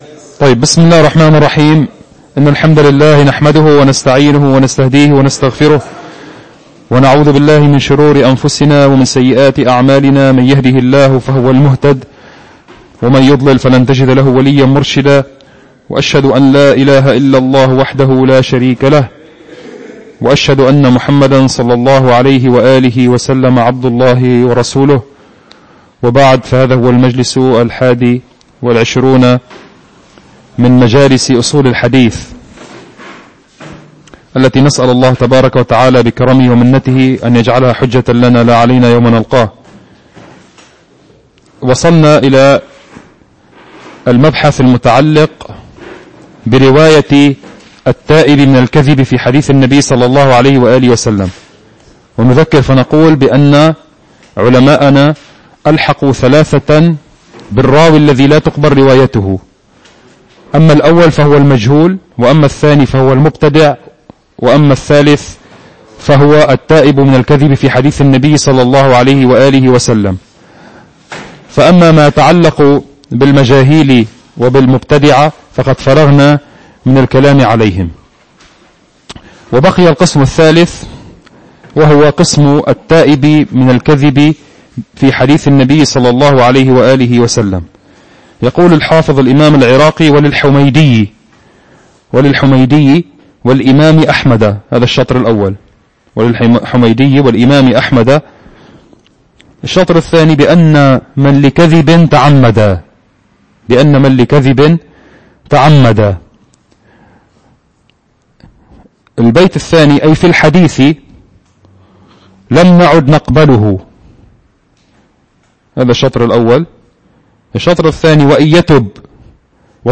المكان : مركز جماعة عباد الرحمن